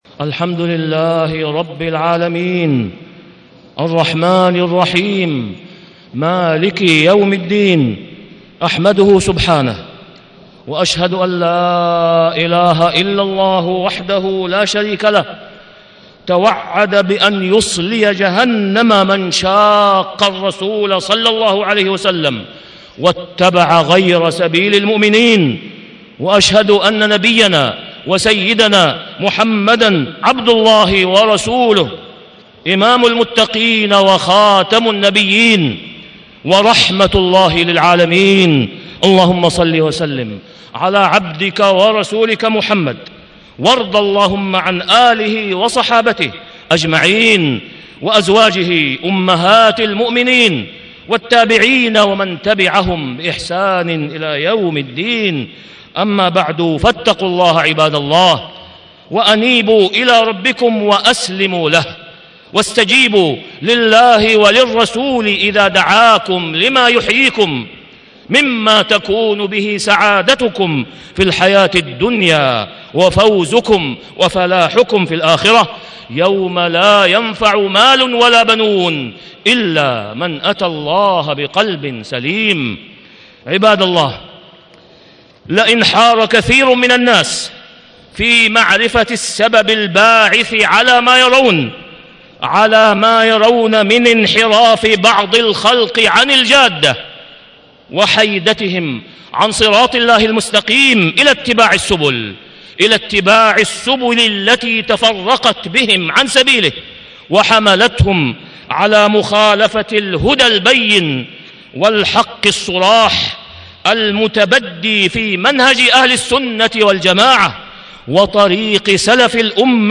تاريخ النشر ١٥ جمادى الأولى ١٤٣٦ هـ المكان: المسجد الحرام الشيخ: فضيلة الشيخ د. أسامة بن عبدالله خياط فضيلة الشيخ د. أسامة بن عبدالله خياط النجاة في اتباع سبيل المؤمنين